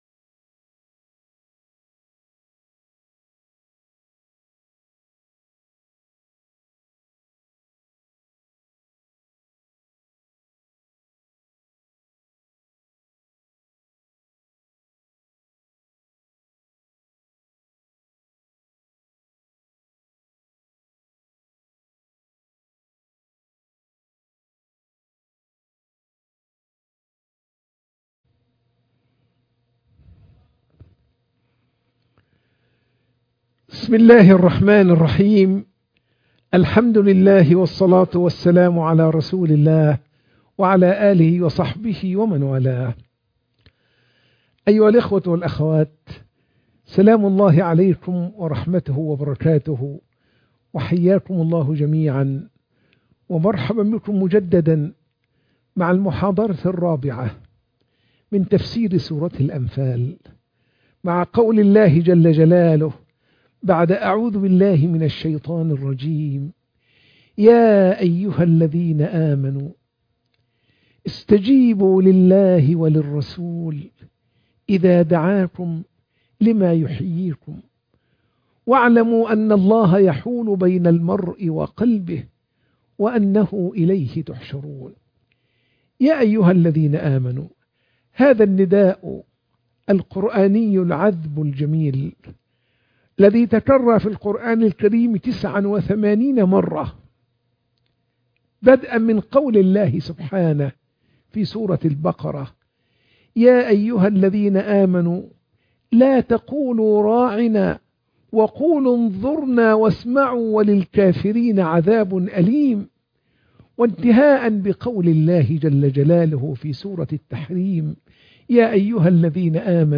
تفسير سورة الأنفال 19 - المحاضرة 4